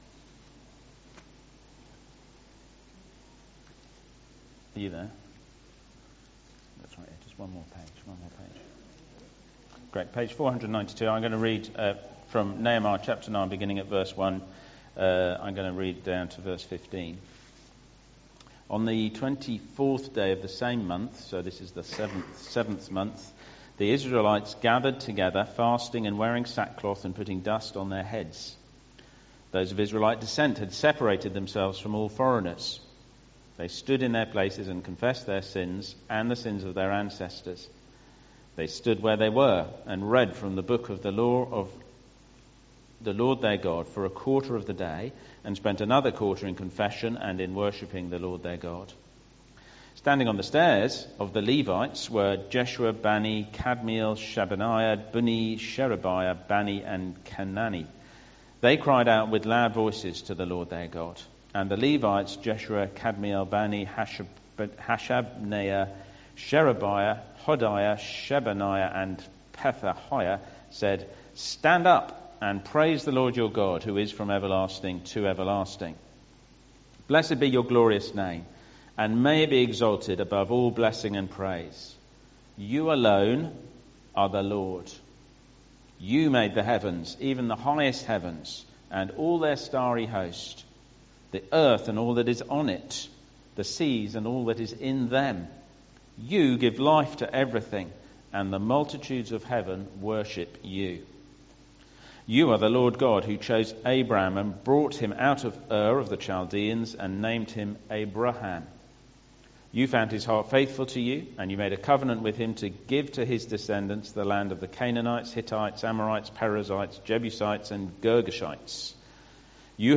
2 Corinthians Jesus leaves a little bit of himself in peoples lives who believe 25/05/2025 Sunday Morning Service 25th May 2025 Dagenham Parish Church.
Passage: 2 Corinthians 2:14-3:18 Service Type: Sunday Morning